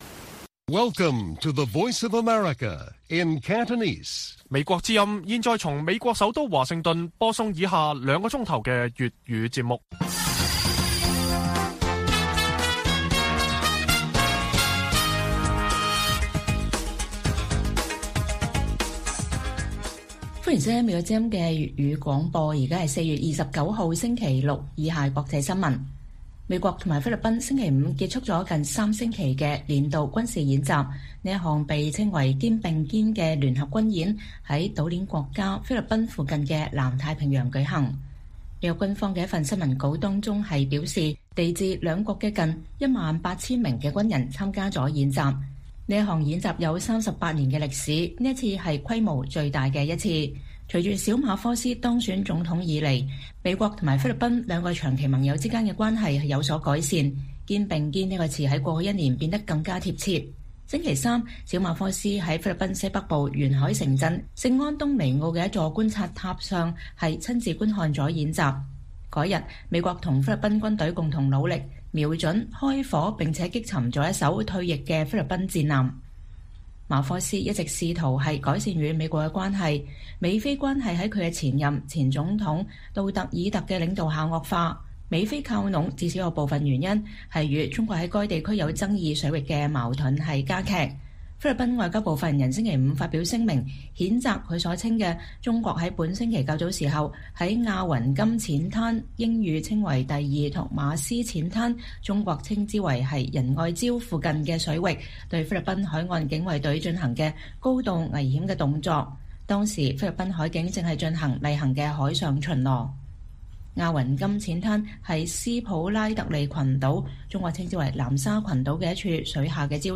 粵語新聞 晚上9-10點：美菲結束大規模聯合軍事演習